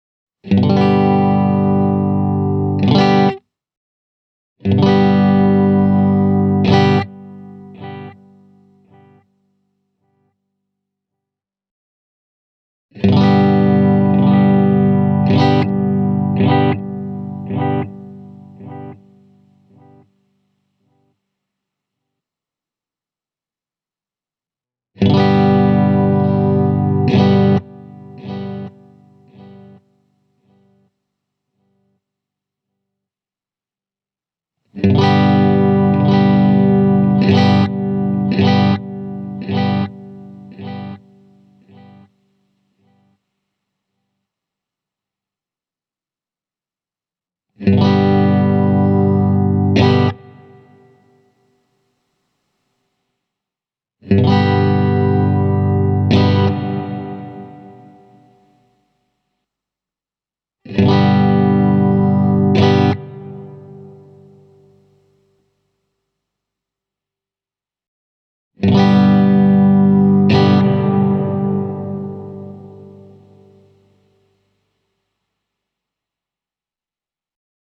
The Delay/Reverb control lets you select one out of two delays or two reverbs.
For this clip I have set the delay time to almost full. I start off with a dry example and then play two examples of each effect, one with low and the other with high intensity: